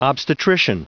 Prononciation du mot : obstetrician
obstetrician.wav